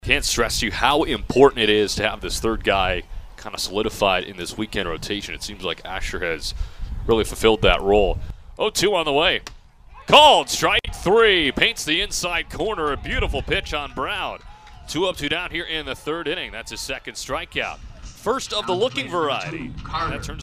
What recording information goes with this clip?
Here is how one of those four Ks sounded on Saturday in South Dakota.